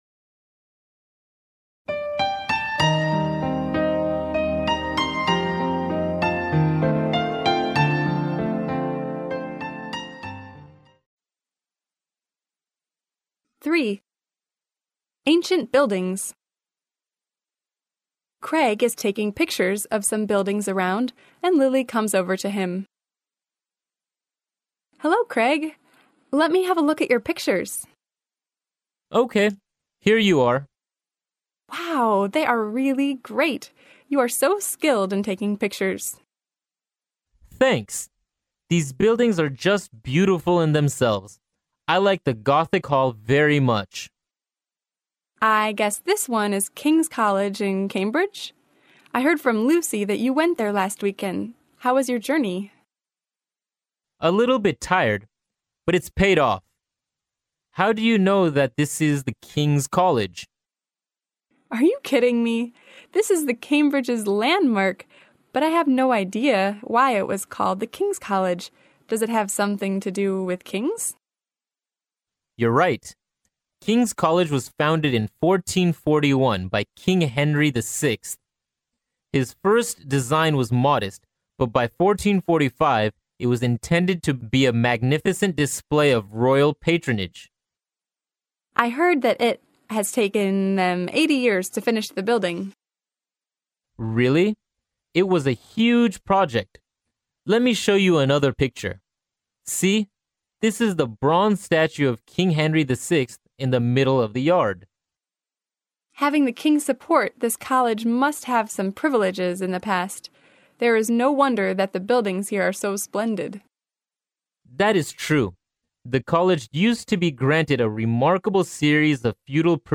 剑桥大学校园英语情景对话03：古老的建筑（mp3+中英）